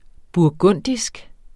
burgundisk adjektiv Bøjning -, -e Udtale [ buɐ̯ˈgɔnˀdisg ] Betydninger 1.